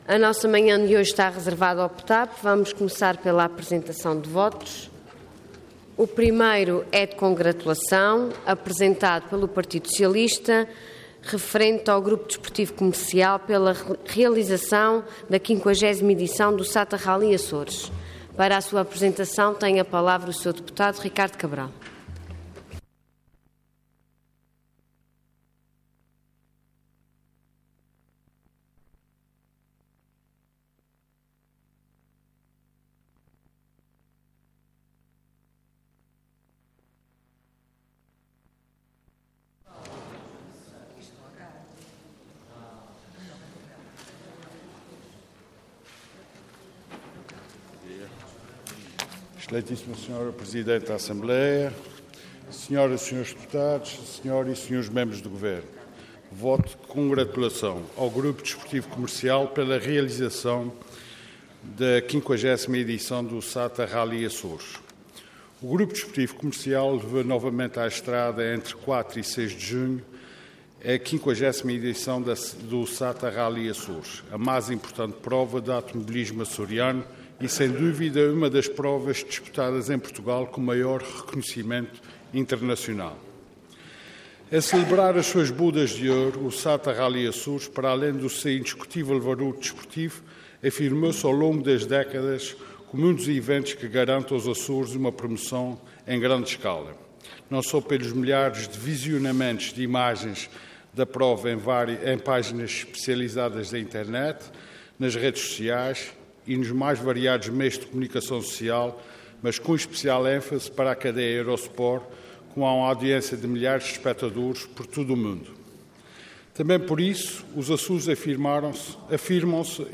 Intervenção Voto de Congratulação Orador Ricardo Cabral Cargo Deputado Entidade PS